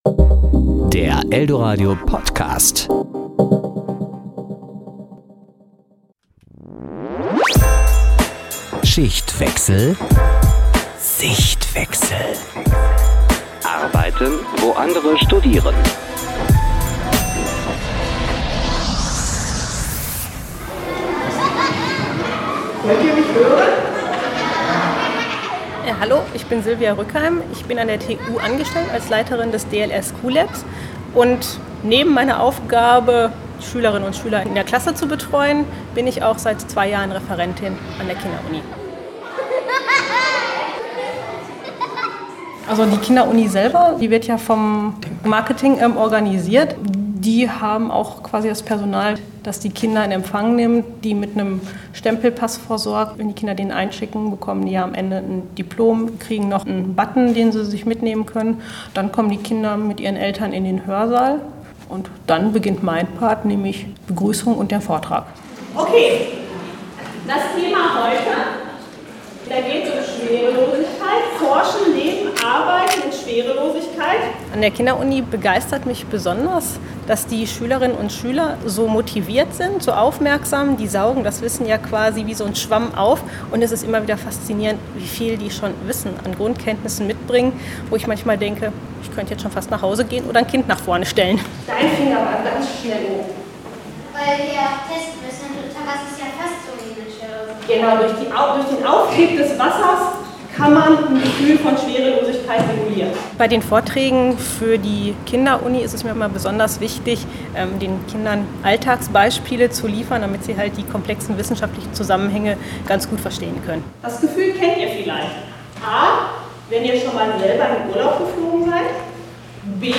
Alle zwei Wochen wimmelt es nur so vor Kindern im Hörsaal an der Emil-Figge Straße.